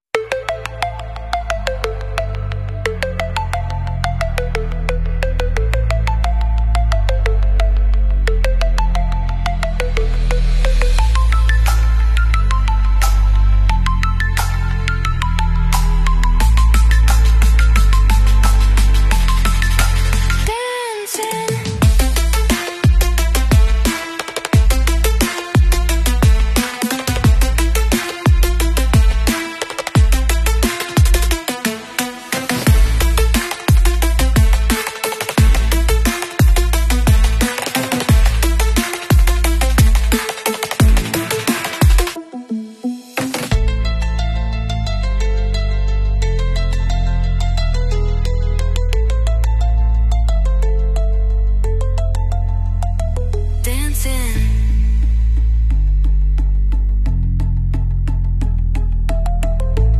Hummingbird dance at the feeder